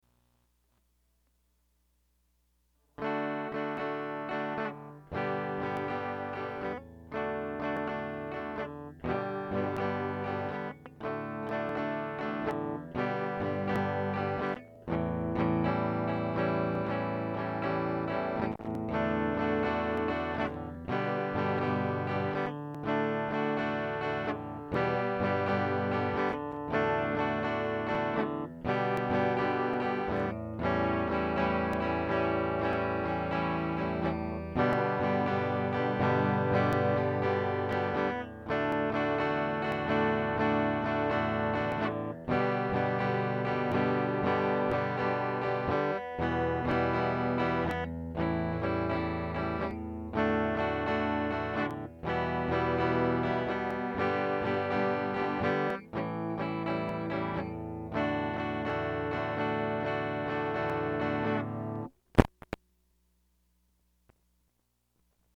First ever public performance!